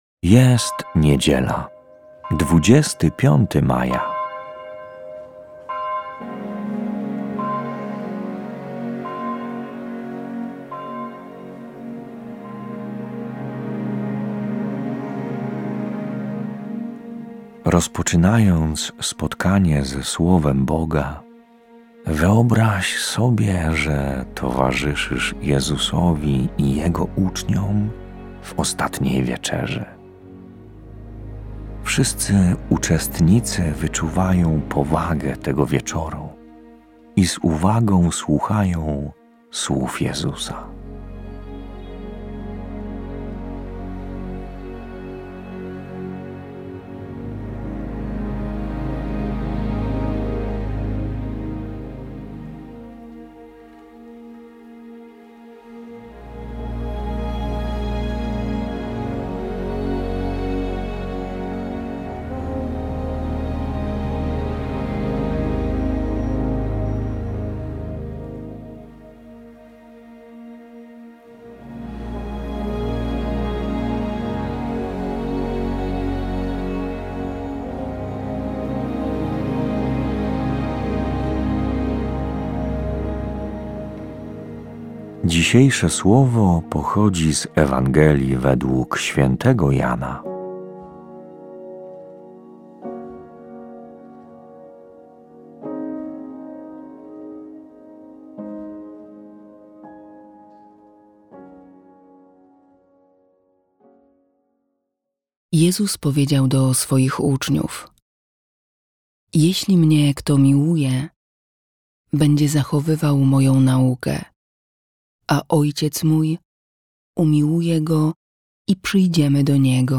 Życzenia i słowo ks. Proboszcza na Wielkanoc